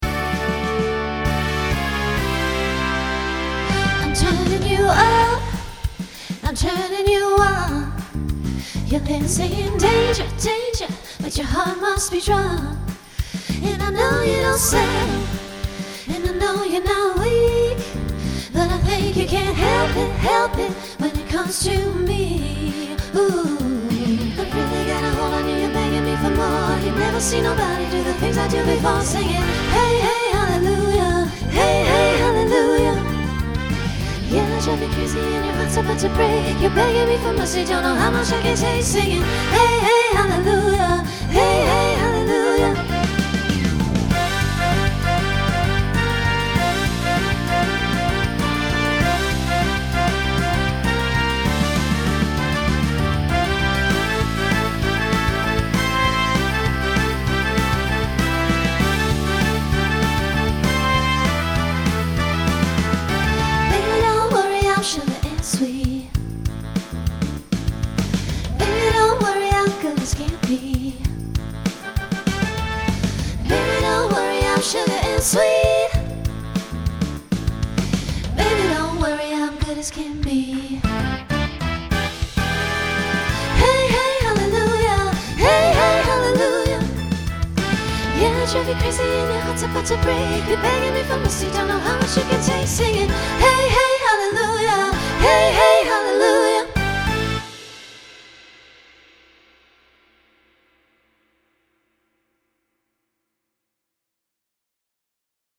Pop/Dance , Rock Instrumental combo
Transition Voicing SSA